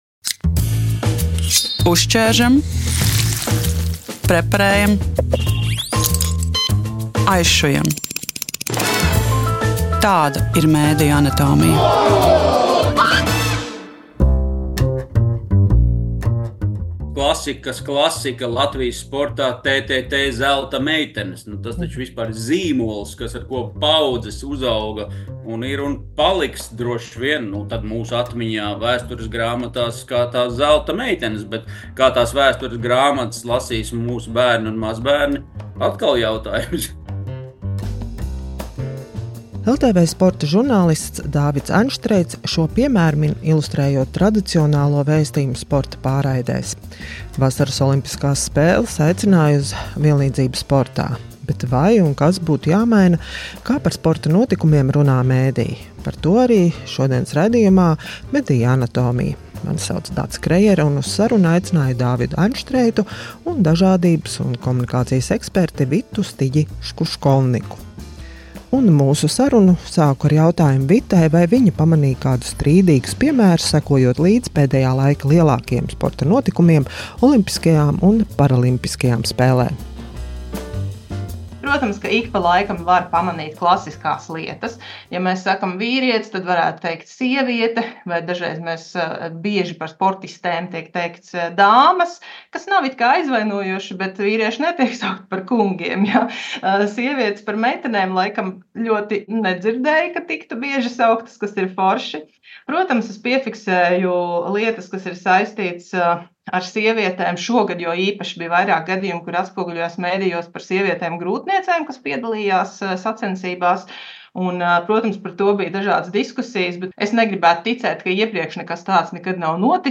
Vai un kā būtu jāmaina naratīvs sporta pārraidēs par sportistēm un parasportistiem? Saruna